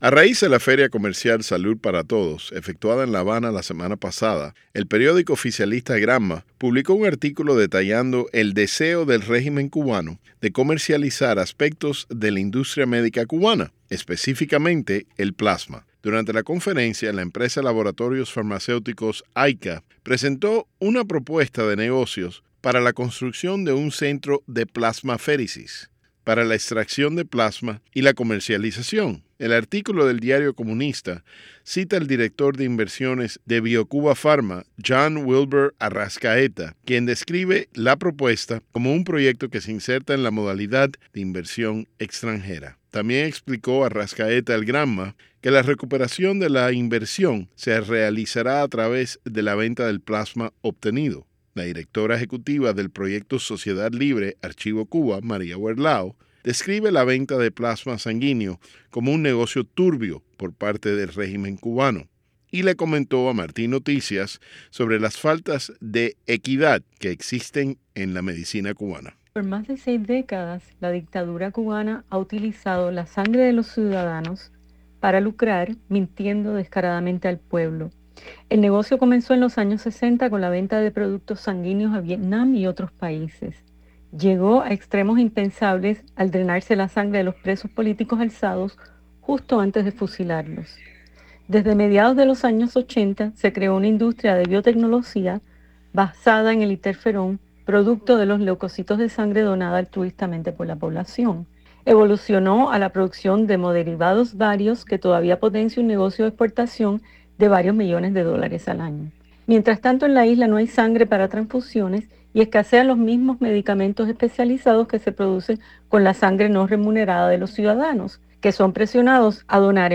En entrevista con Martí Noticias, la activista aseguró que “durante más de seis décadas, la dictadura cubana ha utilizado la sangre de sus ciudadanos para lucrarse, mintiendo descaradamente al pueblo”.